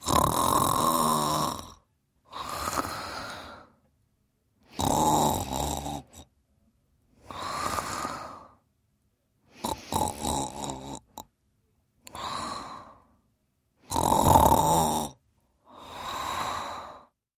Звуки женского храпа
Короткий храп женщины